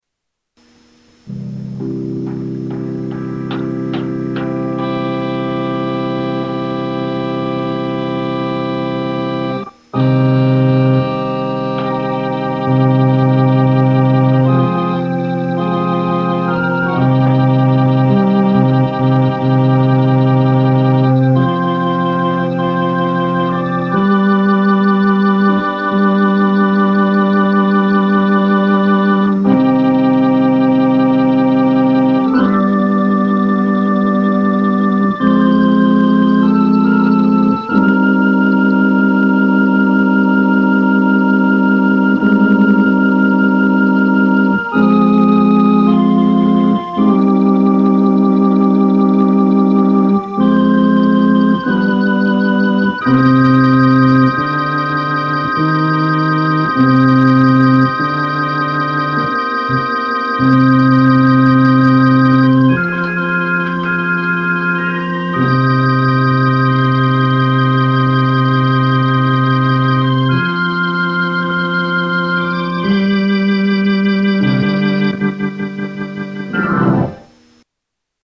Elka Panther Duo 2200 Demo